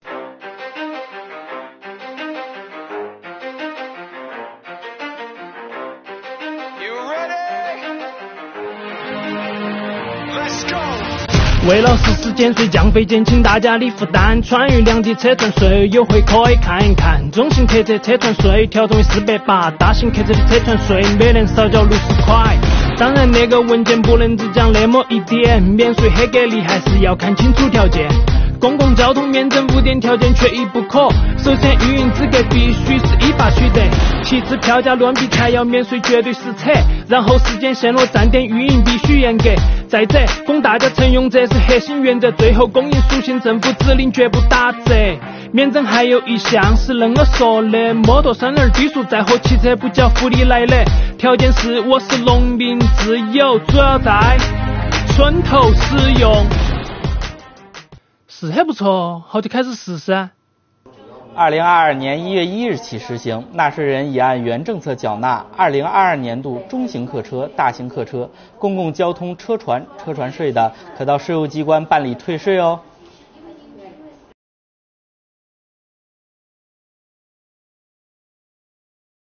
重庆话Rap | 车船税出了新规有优惠，赶紧围观起来~